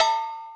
Perc (11).wav